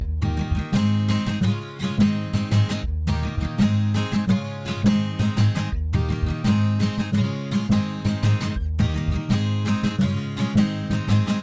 描述：用原声吉他弹奏的民谣和弦。
标签： 原声 民谣 吉他 弹奏
声道立体声